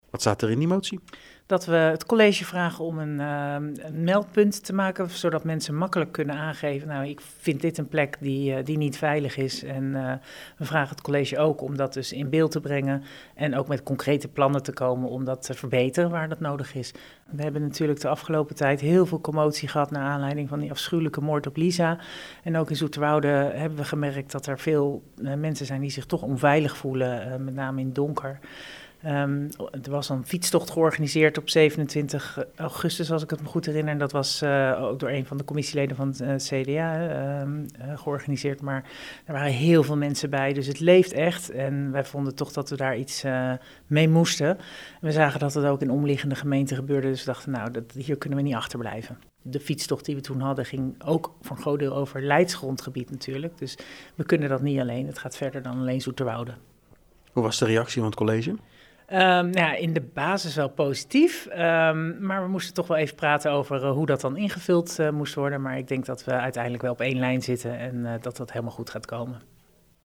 Verslaggever
in gesprek met PZ-fractievoorzitter Myrna van der Poel over de motie ‘Veiligheid voor vrouwen’: